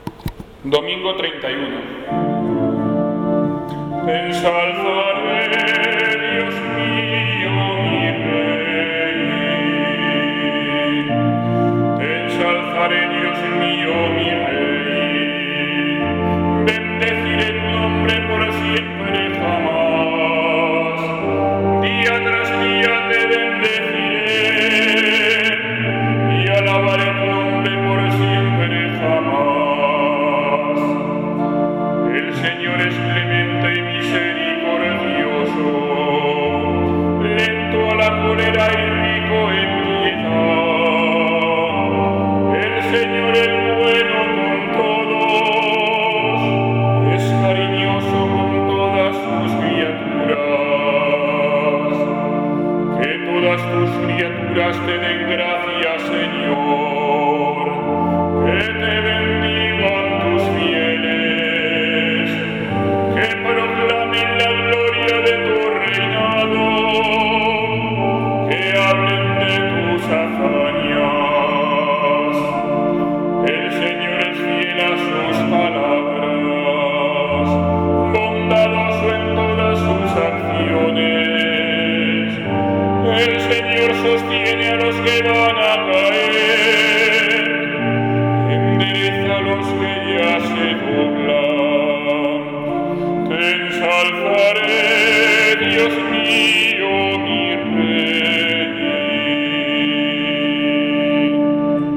Salmo Responsorial [2.573 KB]   Aleluya [1.207 KB]